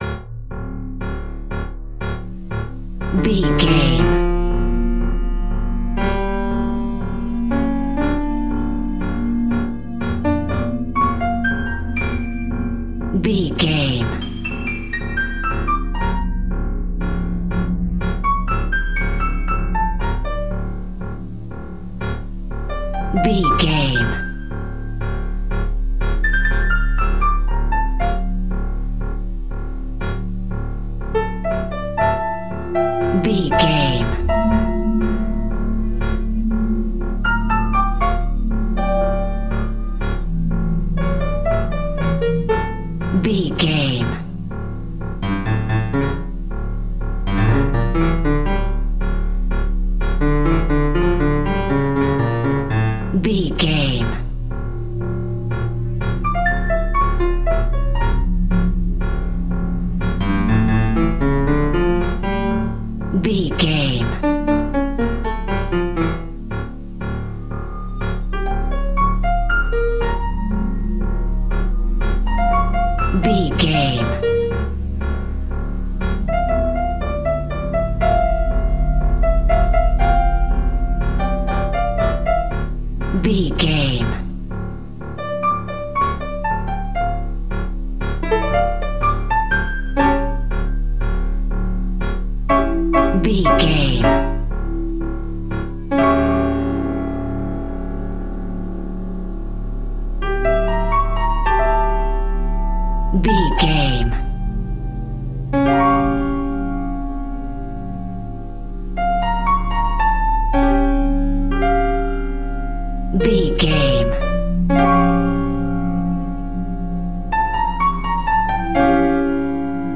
DramaticThriller Chase Music Cue.
Atonal
D
Fast
tension
ominous
dark
suspense
haunting
eerie
piano
viola
orchestral instruments